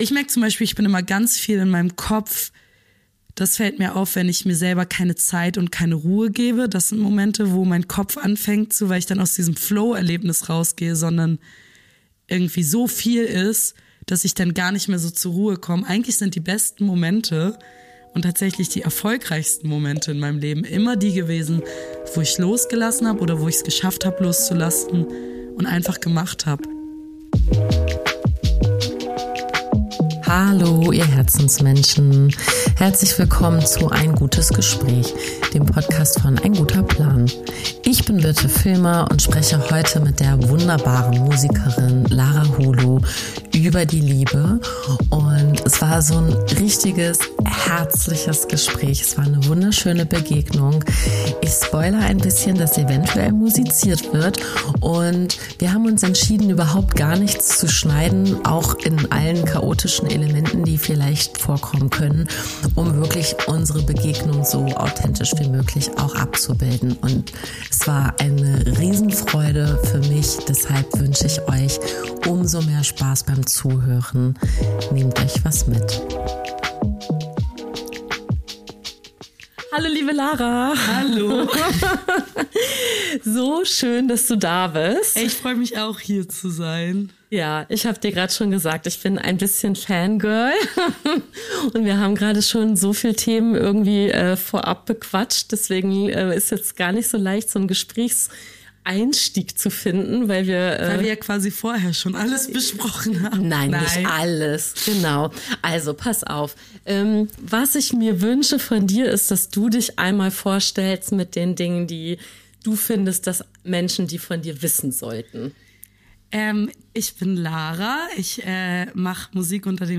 Ein warmes Gespräch über das, was uns antreibt, wo wir zögern und wie wir lieben.